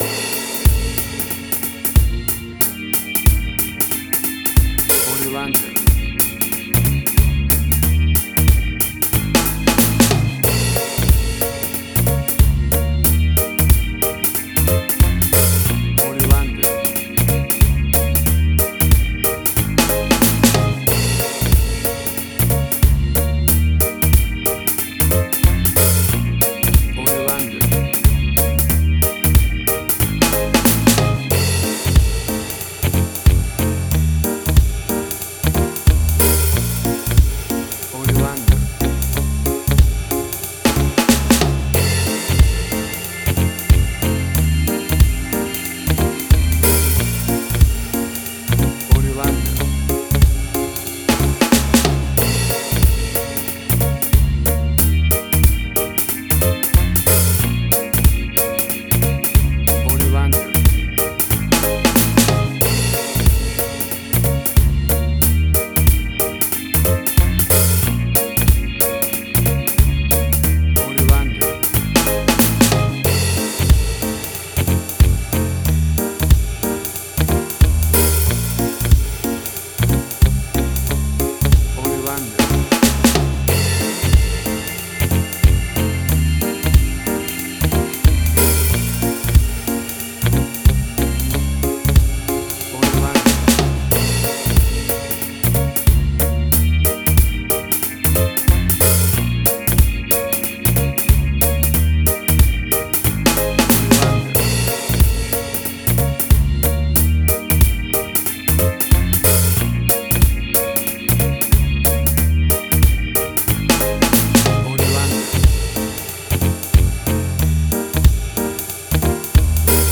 A fast and speedy piece of Reggae music, uptempo and upbeat!
Tempo (BPM): 92